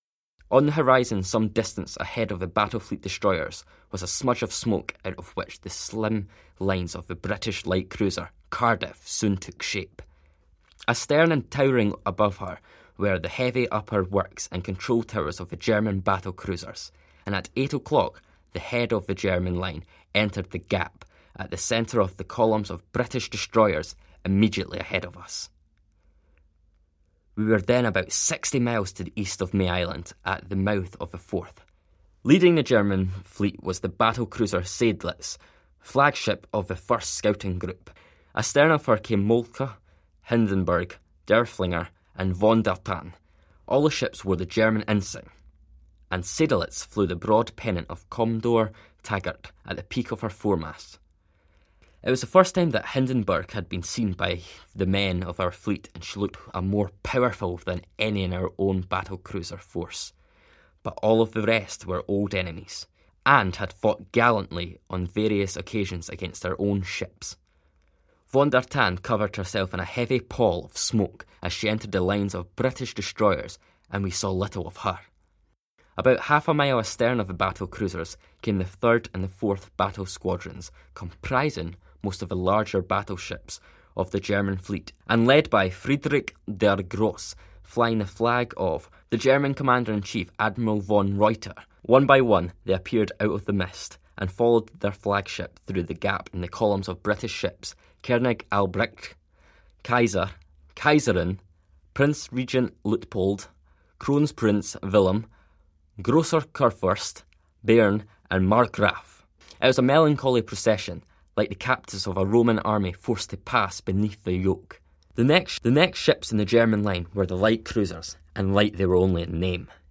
Here is a recording of the final chapter of his diary, covering the Surrender of the German High Seas Fleet in the Forth, November 2018.